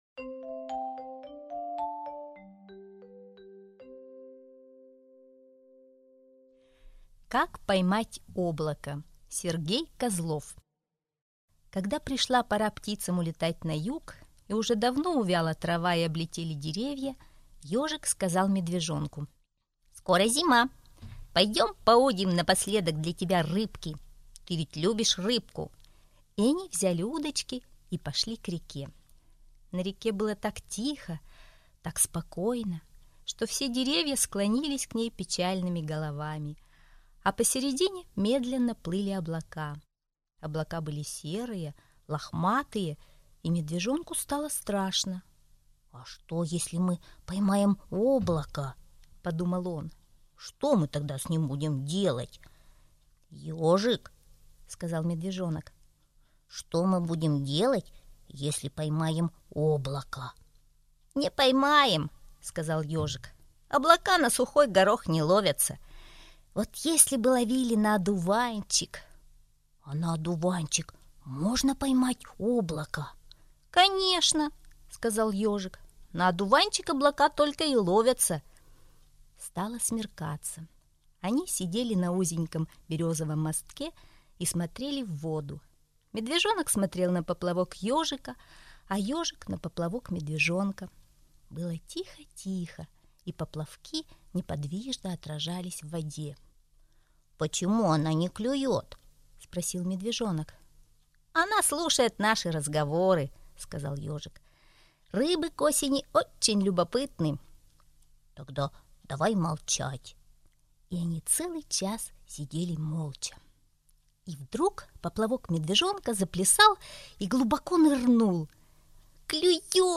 Как поймать облако - аудиосказка Козлова. Сказка про то, как Ежик и Медвежонок ходили осенью на рыбалку, но вместо рыбы у них клюнула луна.